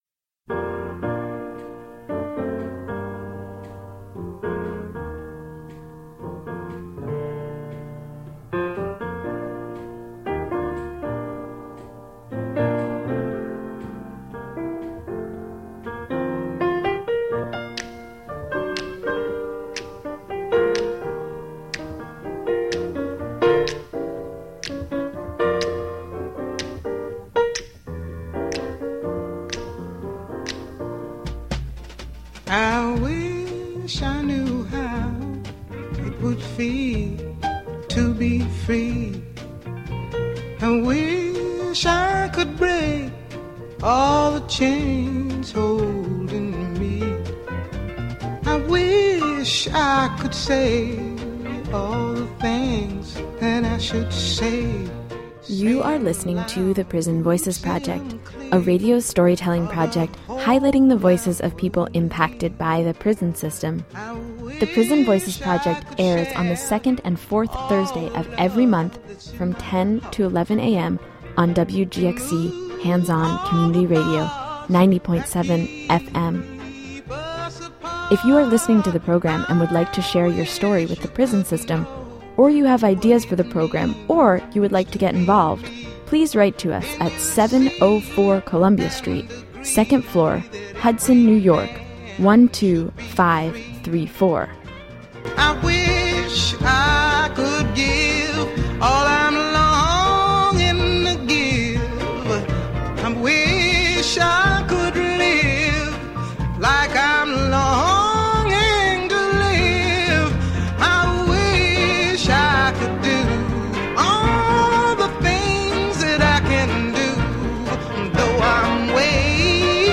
This week's show will be a discussion of the legacy of Assata Shakur, in honor of Assata Shakur Liberation day. The show will include interviews with SUNY New Paltz student members of Students of Mass Incarceration, who organized an event on campus in honor of Assata. It will include an excerpt from a letter written by Assata as well as some of her writing.